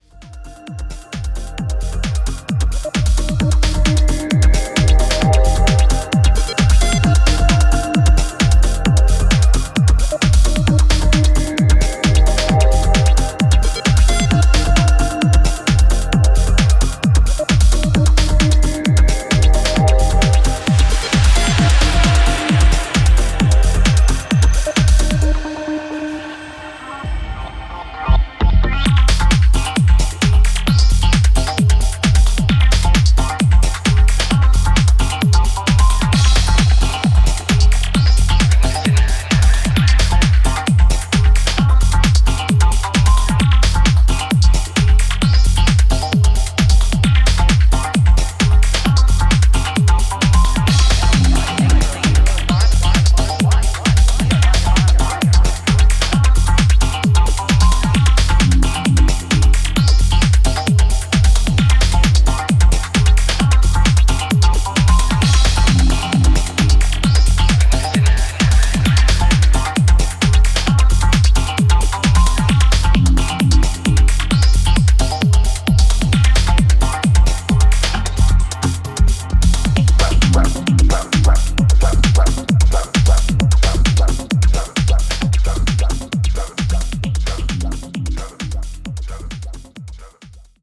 高域の彩りを豊かに飾るエフェクティヴなシンセワークからして、センスが溢れていますね。